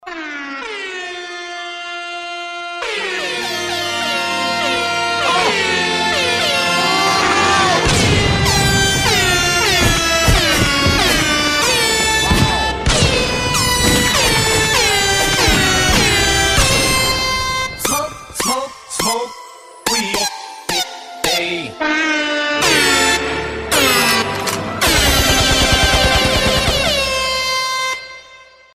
прикольные